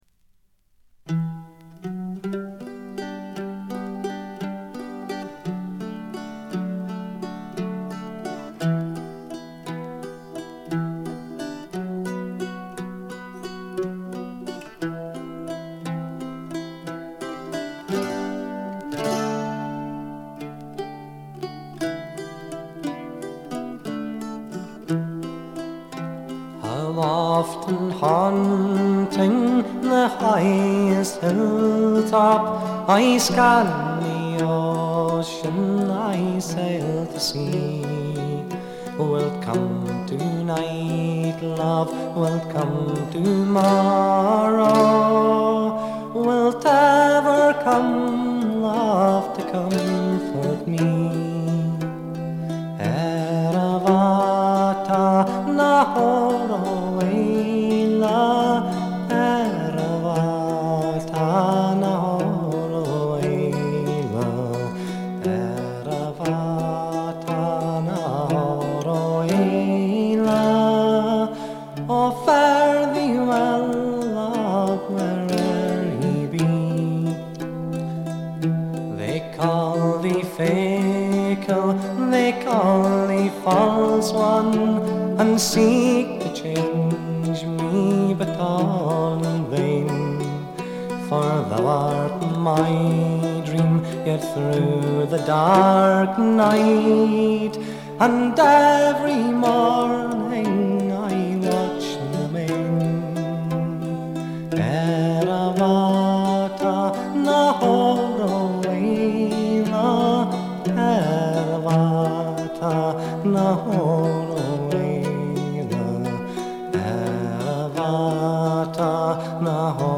70年にエディンバラで結成されたスコットランドを代表するトラッド・バンド。
試聴曲は現品からの取り込み音源です。
Fiddle, viola, bouzouki, mandolin, mandola, vocals
Guitar, mandola